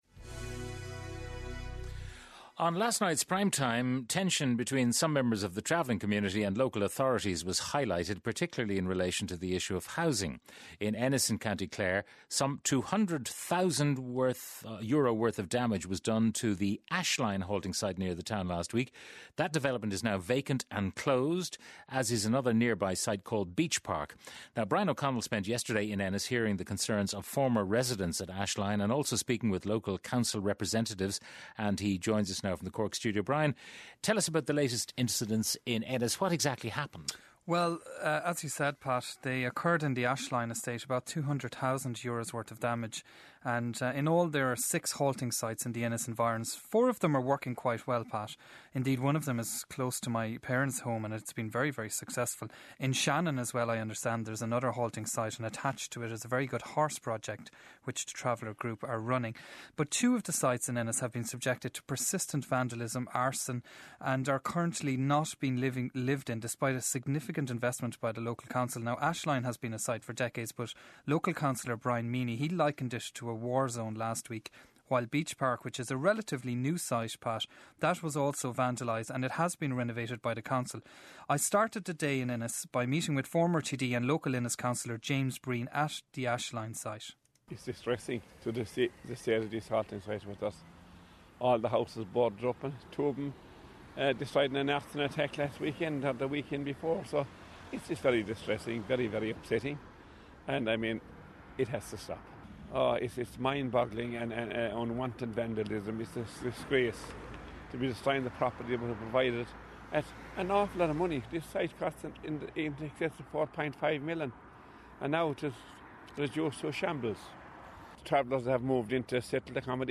I spent some time this week in Ennis hearing the concerns of former residents at Ashline and also speaking with local Council representatives.